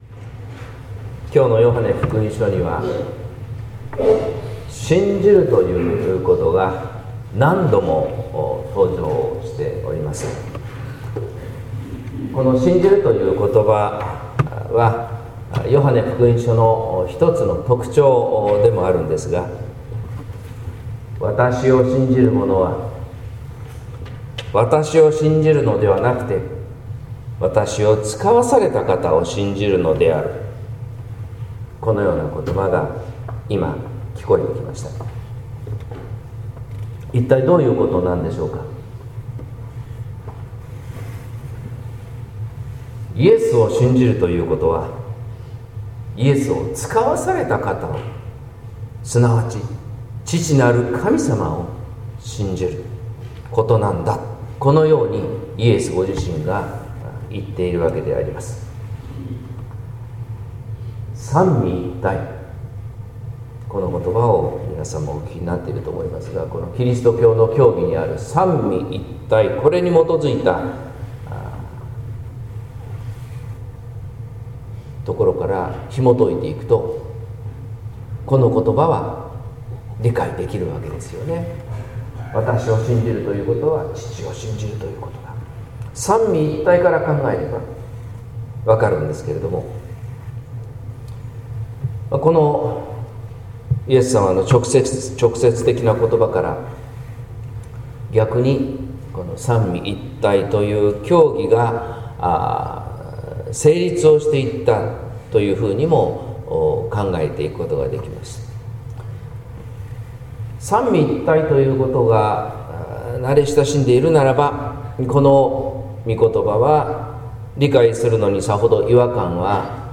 説教「信じるということ」（音声版）